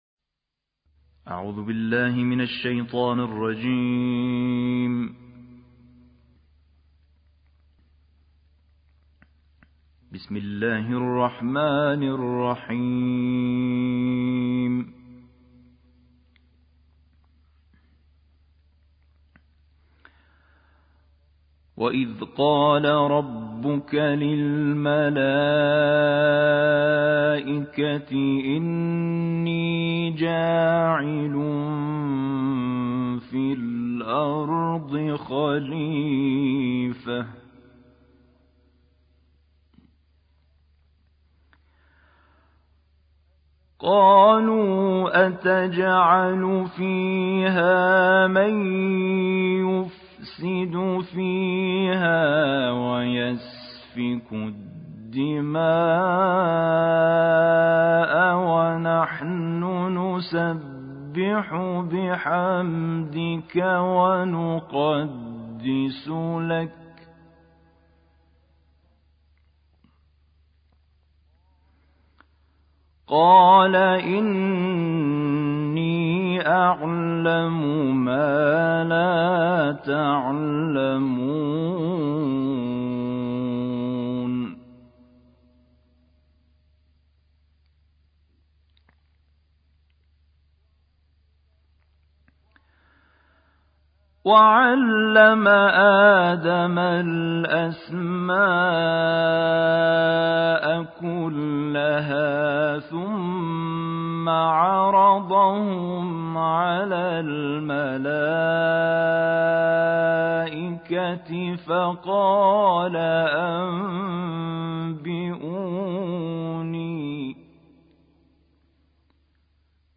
دانلود قرائت سوره بقره آیات 30 تا 46